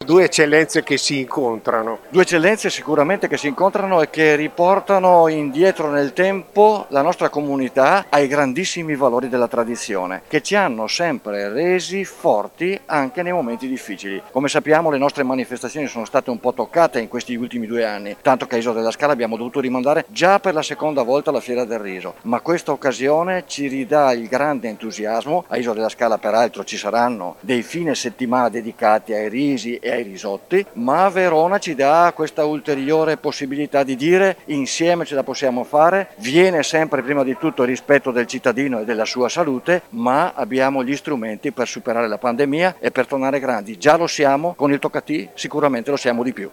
Il Sindaco di Isola della Scala Stefano Canazza: